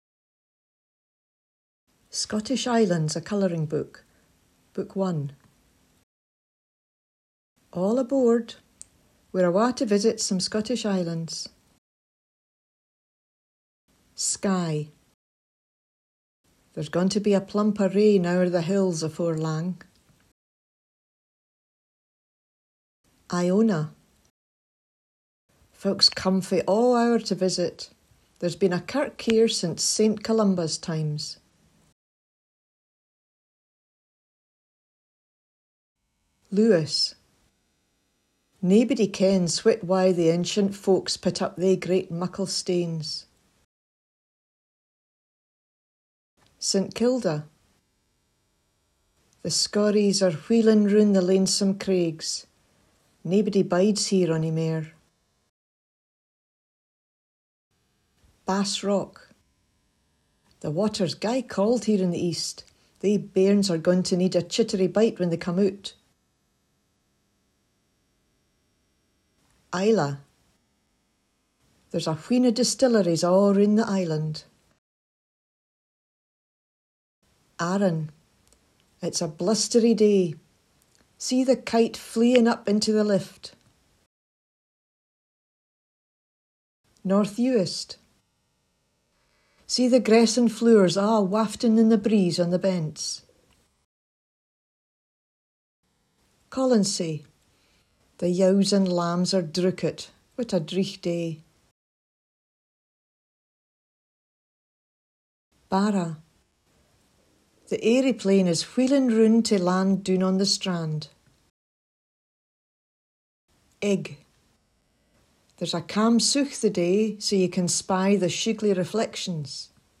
Listen to the Scots being spoken